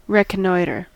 Ääntäminen
IPA : /ˌɹɛkəˈnɔɪtɚ/